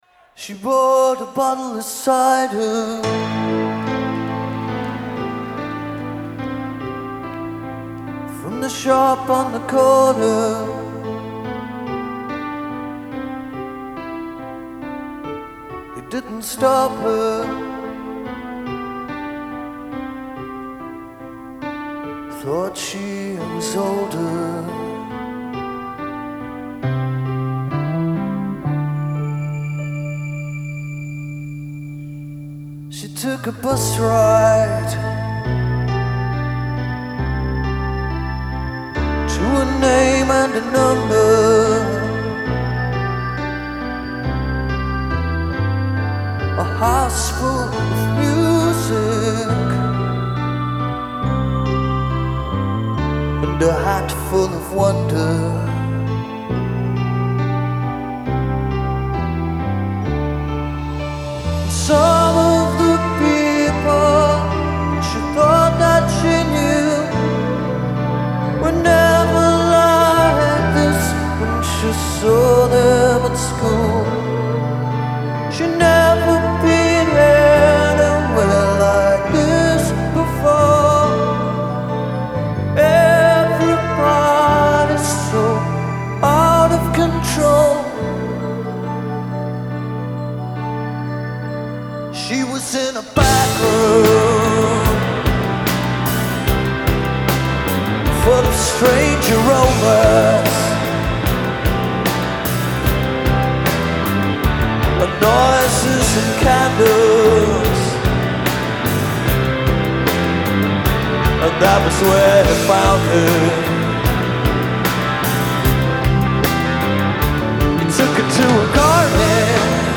Genre : Progressive Rock